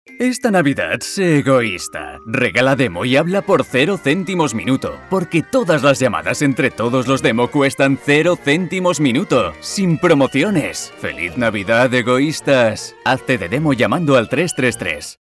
Mi voz es amable. Tengo un amplio abanico de registros: serio, gracioso, institucional...
kastilisch
Sprechprobe: Sonstiges (Muttersprache):